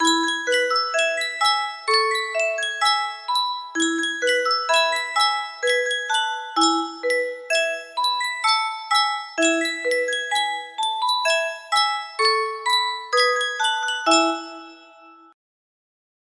Sankyo Music Box - Li'l Liza Jane CXD music box melody
Full range 60